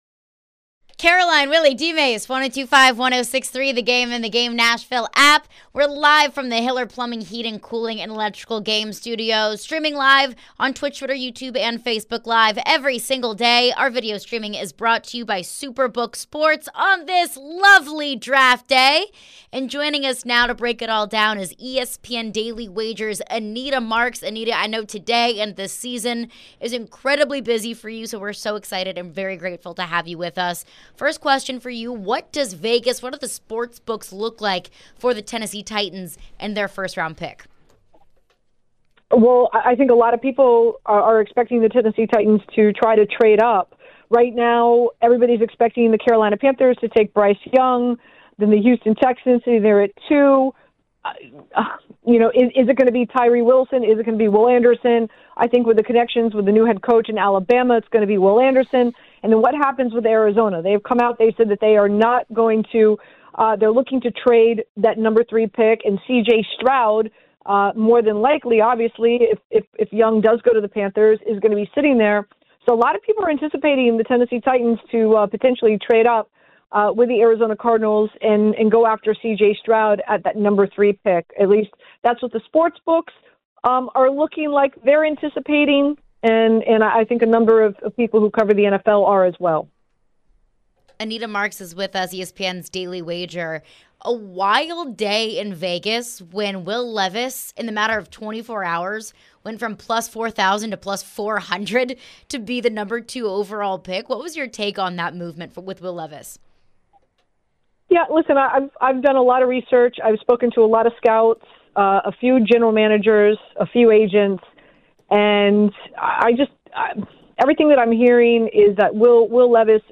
Anita Marks Interview (4-27-23)